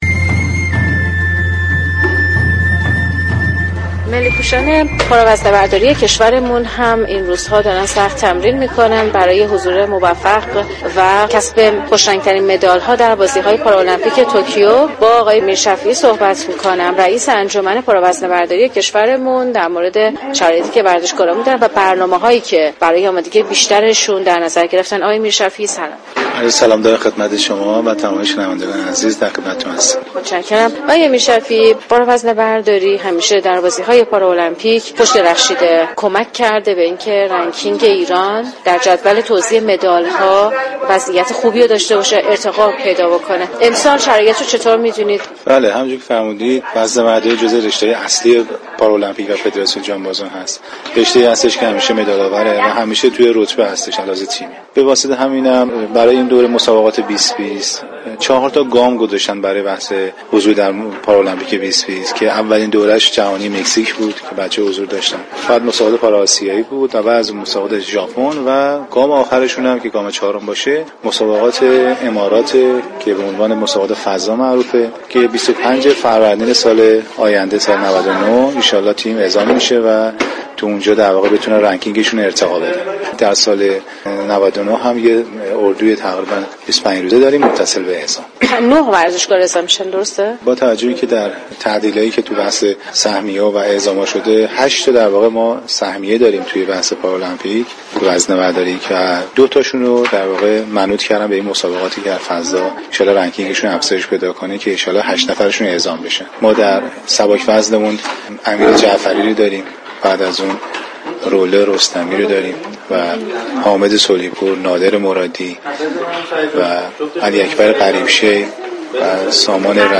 برنامه